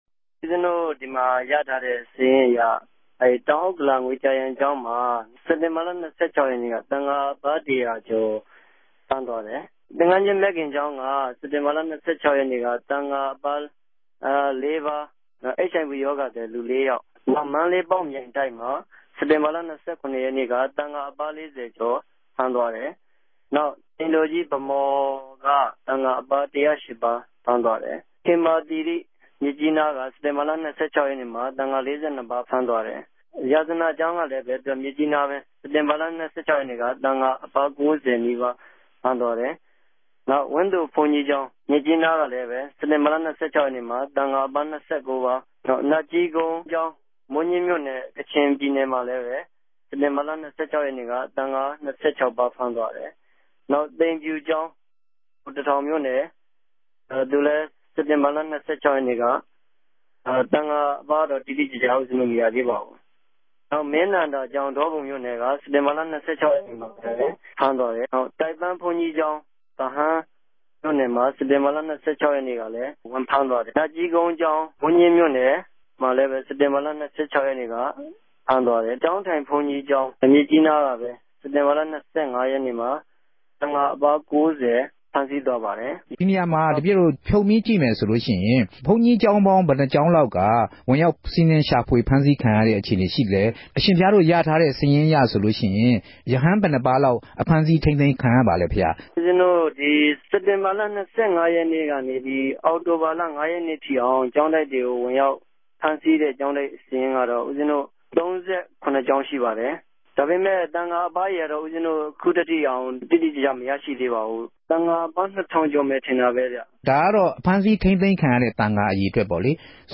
ဆက်သြယ် လ္တေွာက်ထားမေးူမန်းခဲ့ပၝတယ်။